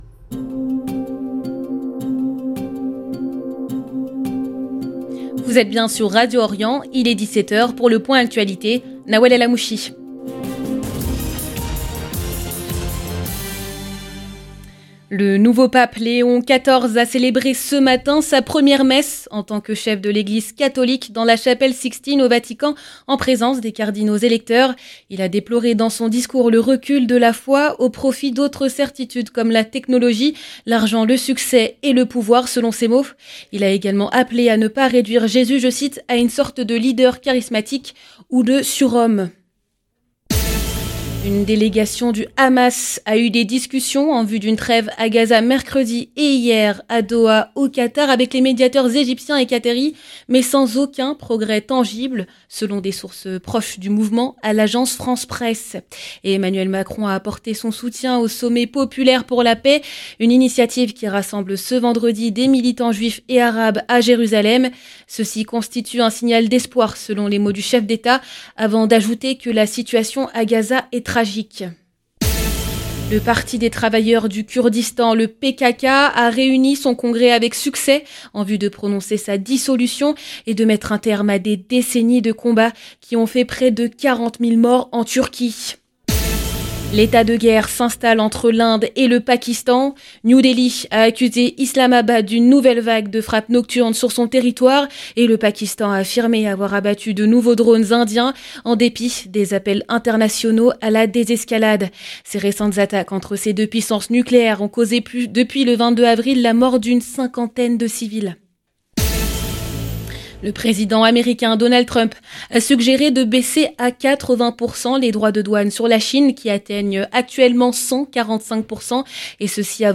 Magazine de l'information du vendredi 9 mai 2025